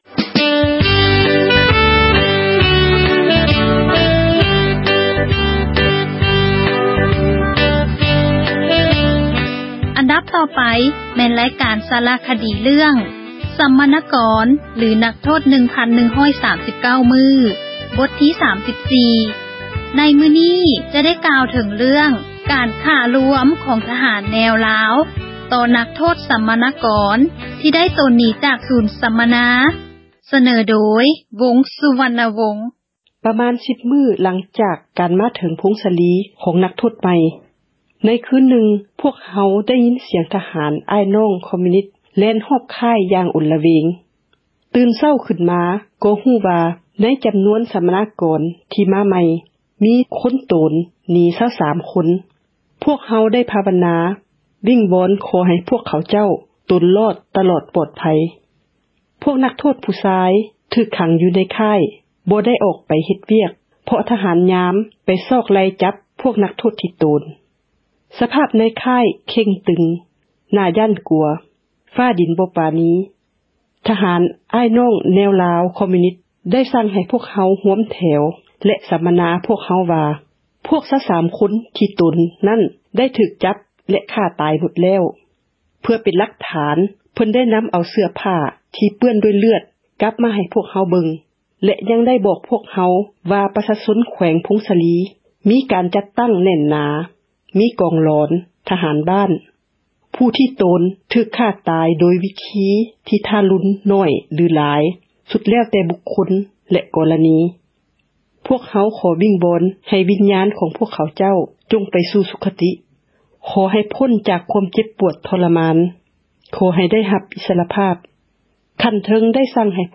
ສາຣະຄະດີ ເຣື້ອງ ສັມມະນາກອນ ຫຼື ນັກໂທດ 1139 ມື້, ມື້ນີ້ ຈະເວົ້າເຖິງ ເຣື້ອງ ການຂ້າລວມ ຂອງ ທະຫານ ແນວລາວ ຕໍ່ນັກໂທດ ສັມມະນາກອນ ທີ່ໂຕນໜີ ຈາກ ສູນສໍາມະນາ.